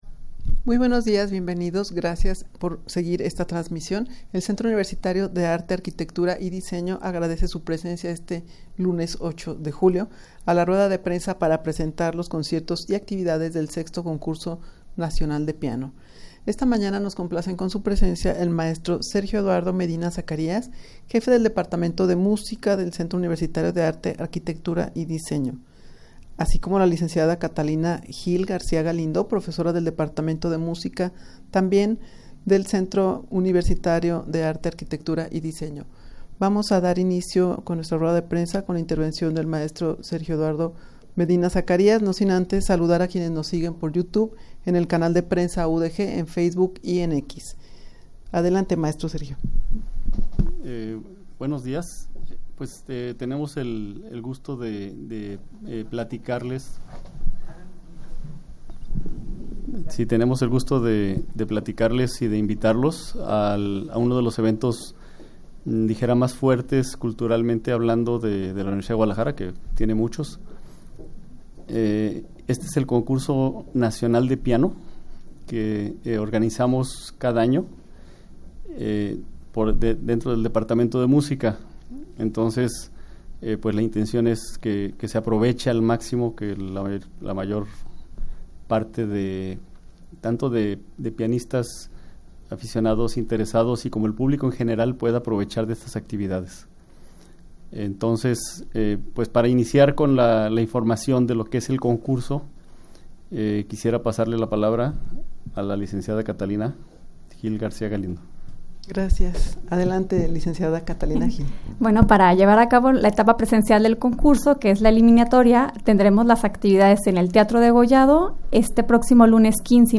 rueda-de-prensa-para-presentar-los-conciertos-y-actividades-del-vi-concurso-nacional-de-piano.mp3